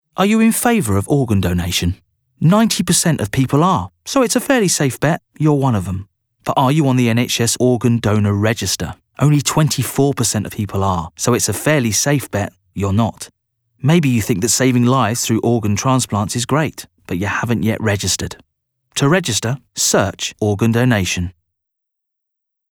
• Male
Showing: Commerical Clips
Gentle, Warm, Informative